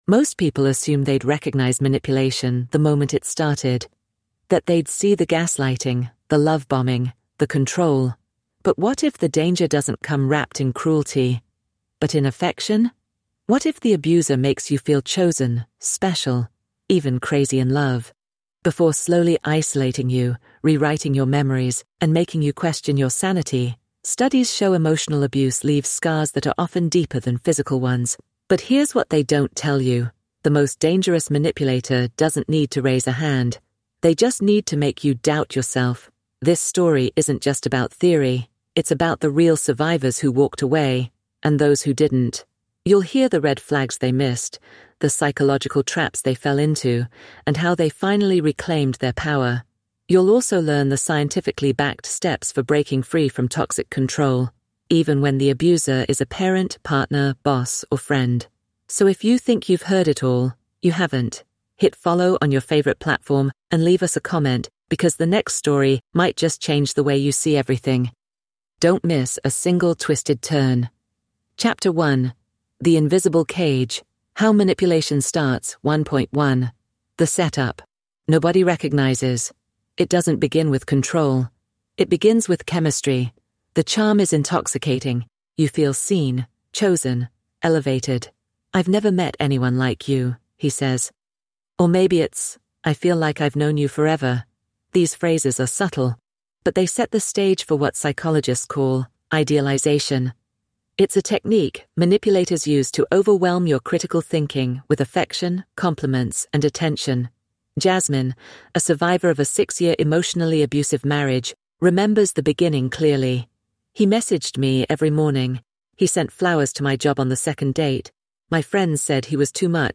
This documentary-style deep dive exposes the hidden psychological traps behind emotional abuse — from gaslighting and love bombing to trauma bonding and identity erasure. You’ll hear the real stories of survivors who escaped, plus step-by-step strategies for reclaiming your power, setting unshakable boundaries, and rewriting what love truly means.